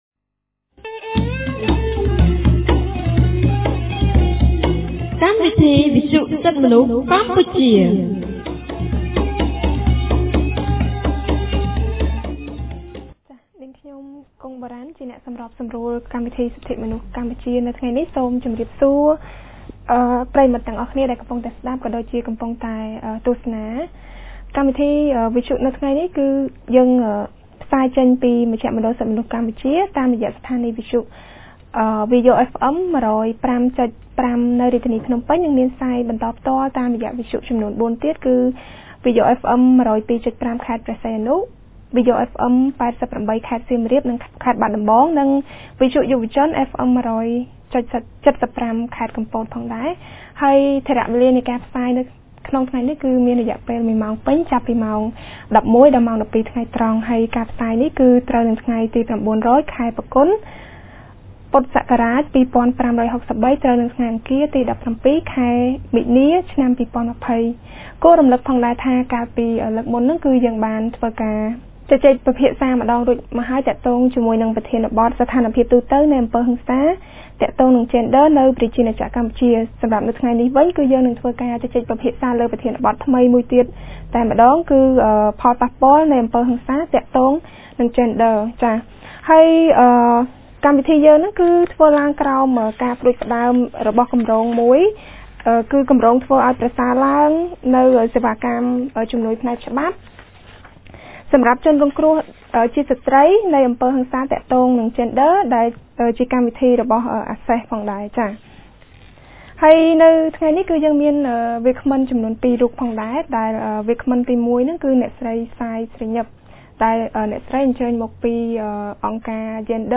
ថ្ងៃអង្គារ ទី១៧ ខែមីនា ឆ្នាំ២០២០ គម្រាងសិទ្ធិទទួលបានការជំនុំជម្រះដោយយុត្តិធម៌នៃមជ្ឈមណ្ឌលសិទ្ធិមនុស្សកម្ពុជា បានរៀបចំកម្មវិធីវិទ្យុក្រោមប្រធាន បទស្តីពី ផលប៉ះពាល់នៃអំពើហិង្សាទាក់ទងនឹងយេនឌ័រ ។